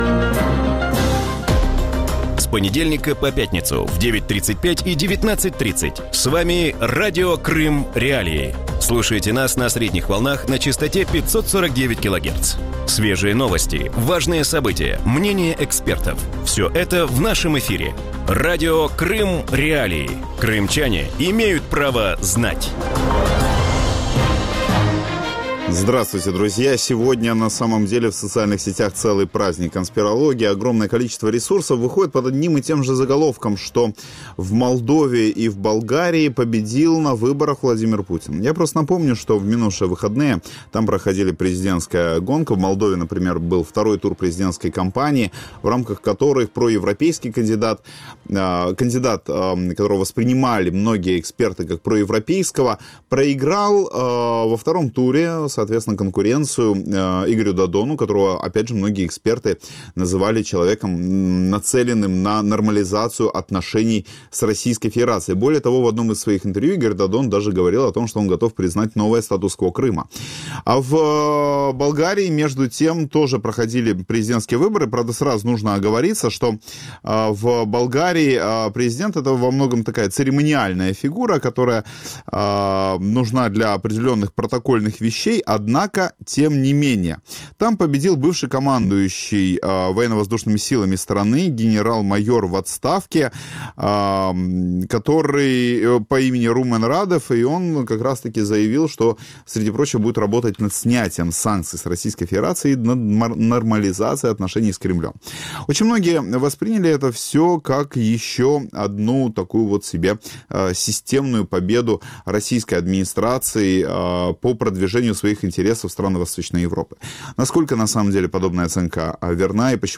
В вечернем эфире Радио Крым.Реалии говорят о результатах президентских выборов в Молдове и Болгарии. Почему в этих странах к власти пришли политики с пророссийскими взглядами, как Кремль влияет на внутреннюю политику в Болгарии и Молдове и могут ли эти страны признать аннексию Крыма?